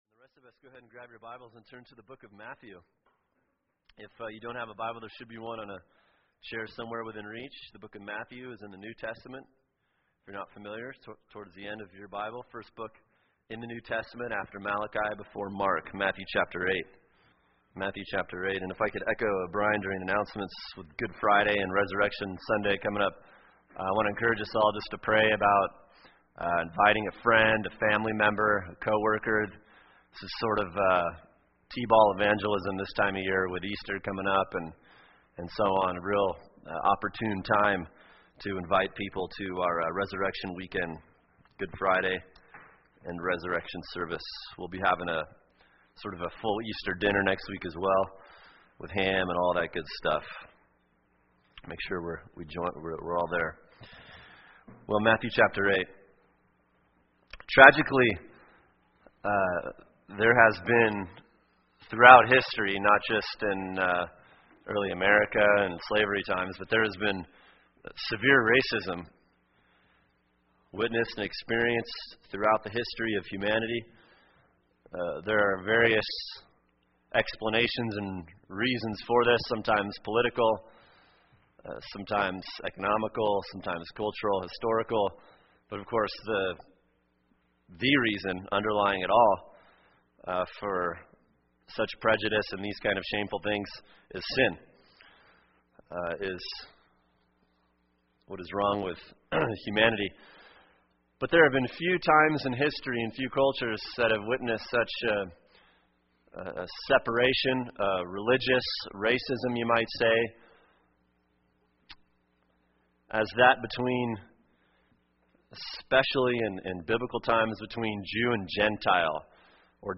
[sermon] Matthew 8:5-13 “Unlikely Saving Faith” | Cornerstone Church - Jackson Hole